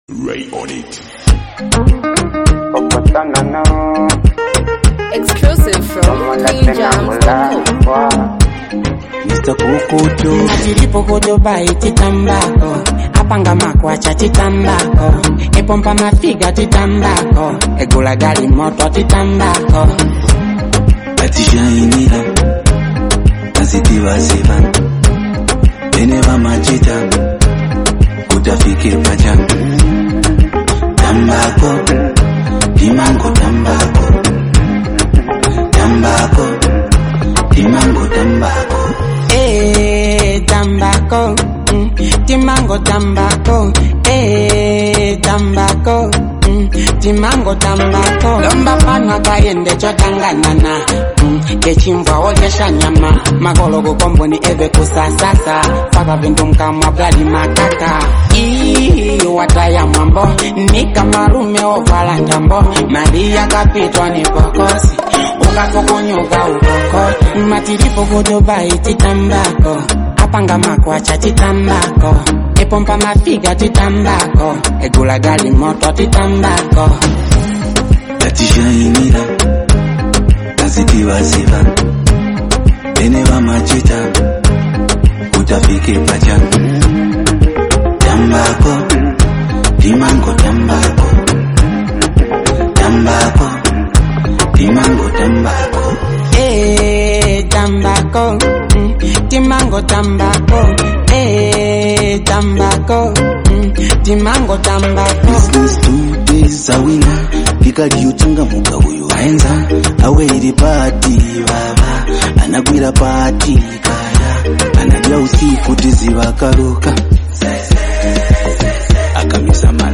hype, street vibes, and a powerful hook
unique singing flow